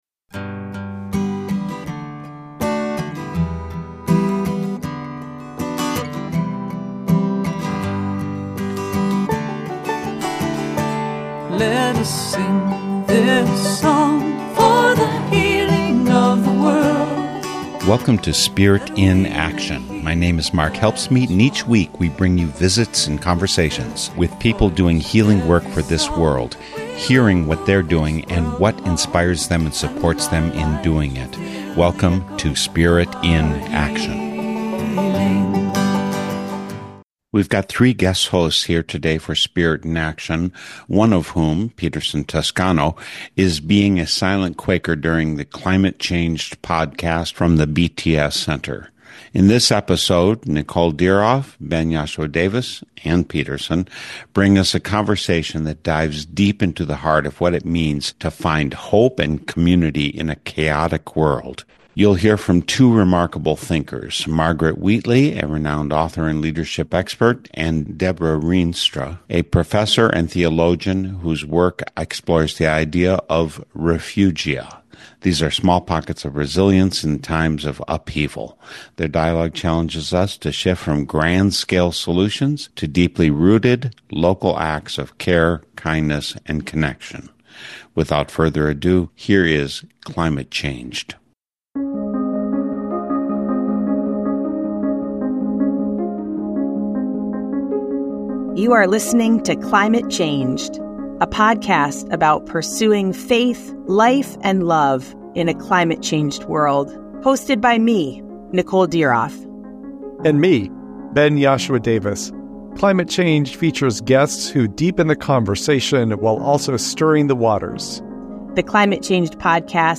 In this episode they bring us a conversation that dives deep into the heart of what it means to find hope and community in a chaotic world.